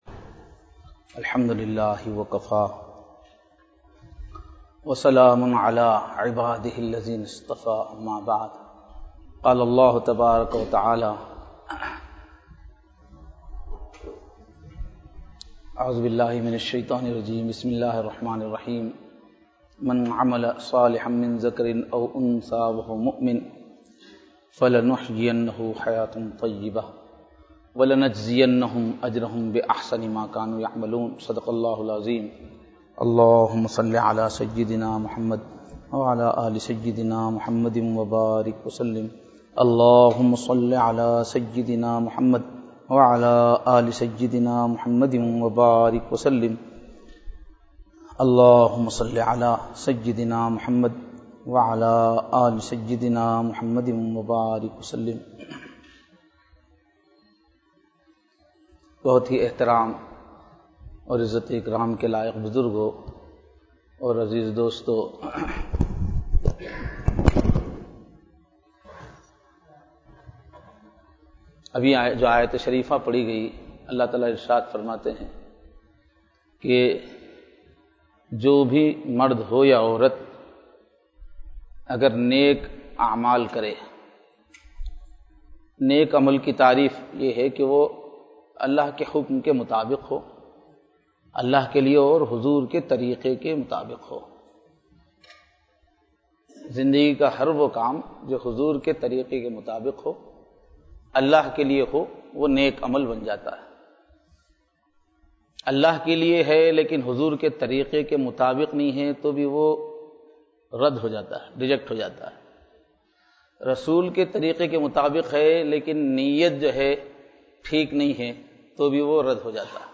JUMMA BAYAN 13/05/2016